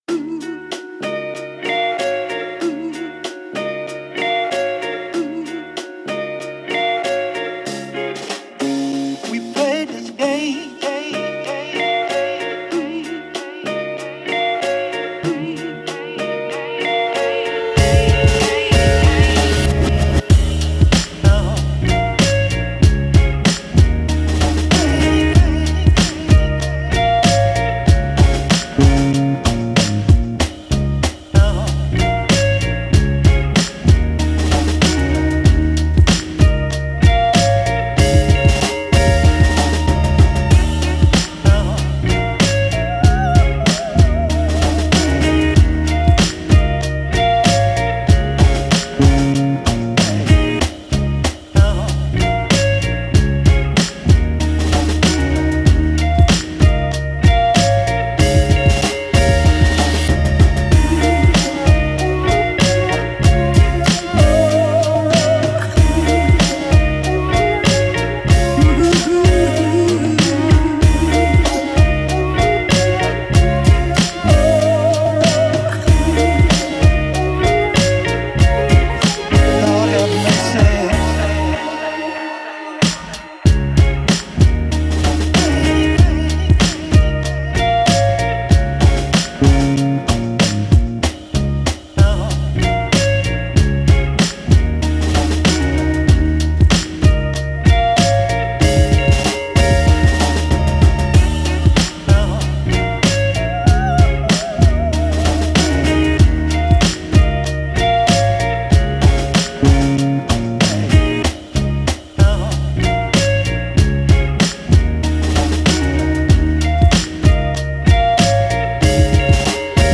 Boom Bap Instrumentals